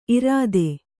♪ irāde